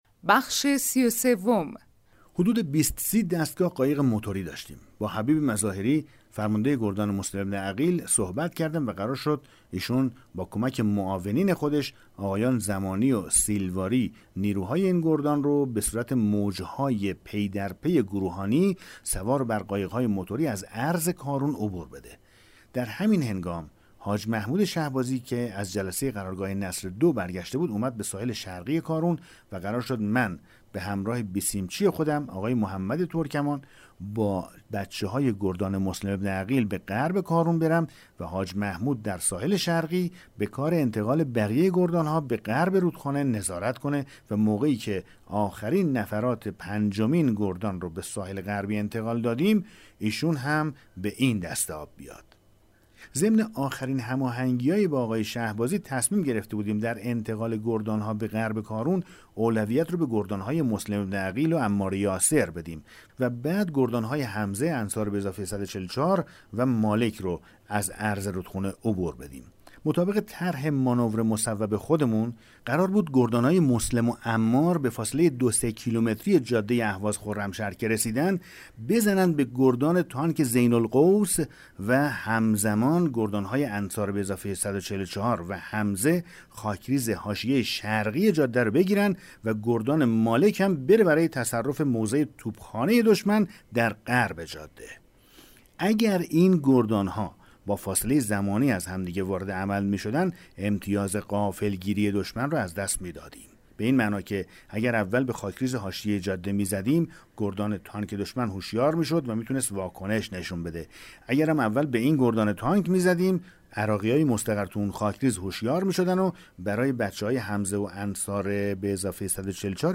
کتاب صوتی پیغام ماهی ها، سرگذشت جنگ‌های نامتقارن حاج حسین همدانی /قسمت 33